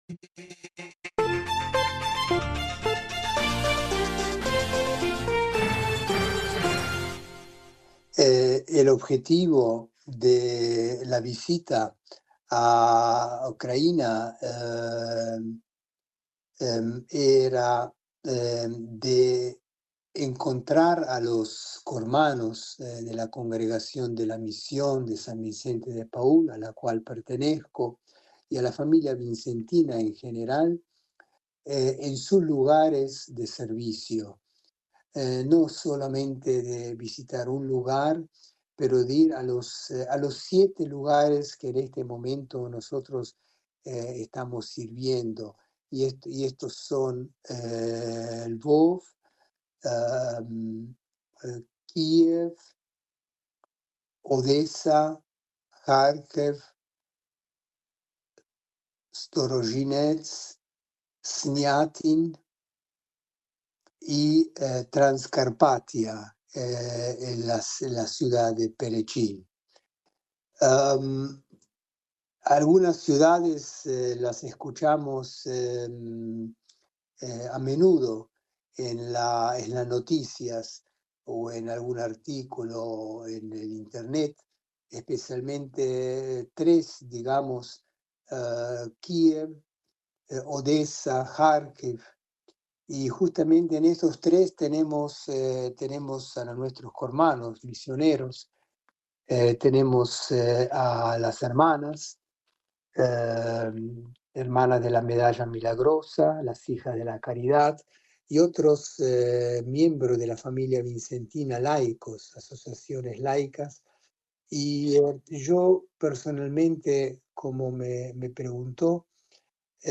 (Tagstotranslate) Solidarity (T) Interview Russia (T) (T) Ukraine (T) Religious Orders (T)